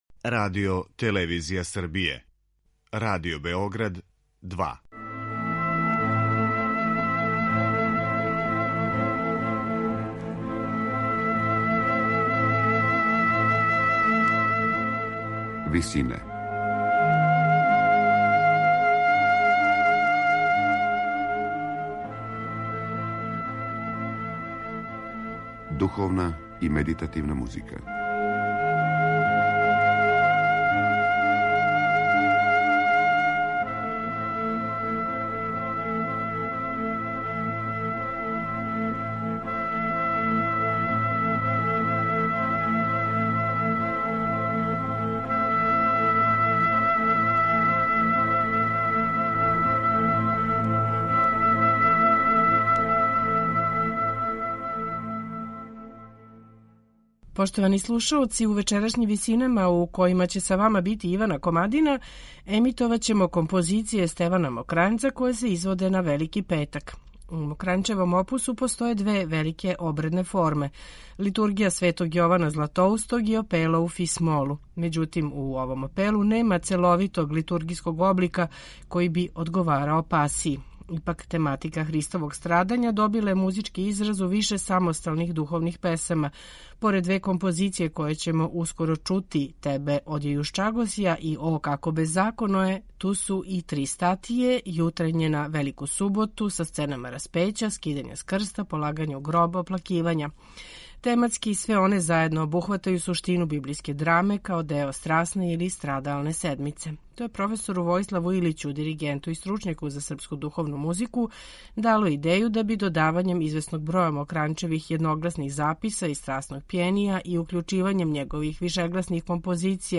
У вечерашњим Висинама чућемо три композиције из циклуса Страсна седмица Стевена Мокрањца: Да исправитсја, О како безаконоје и Тебе одјејушчагосја. Интерпретацију су остварили Хор Радио телевизије Београд